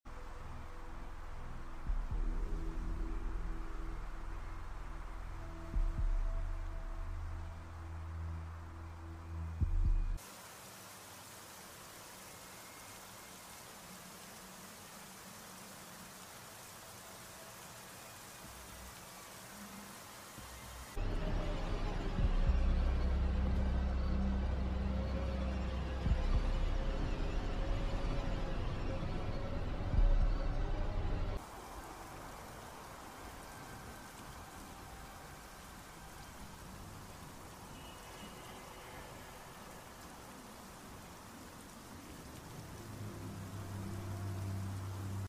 The atmosphere of Gears of War 4